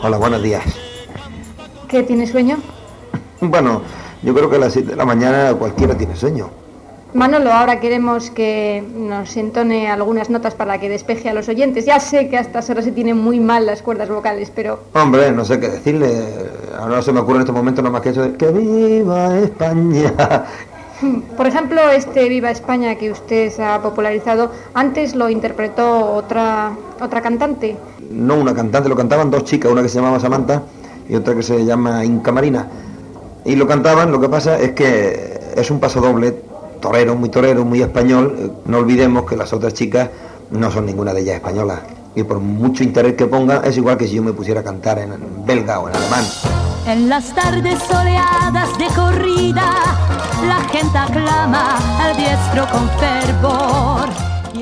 Entrevista al cantant Manolo Escobar